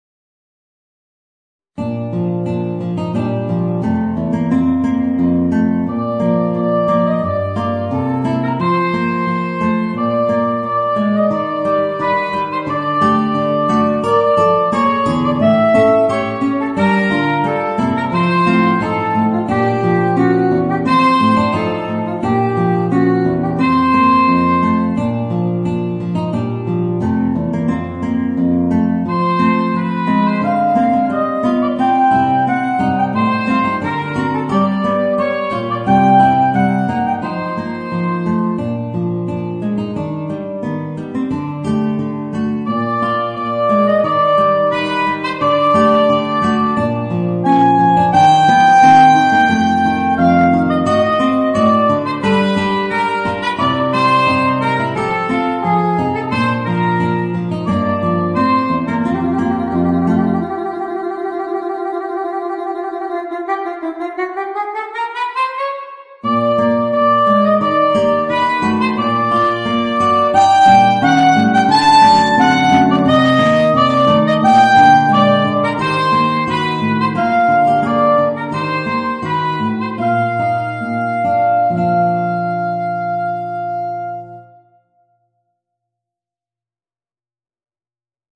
Voicing: Guitar and Soprano Saxophone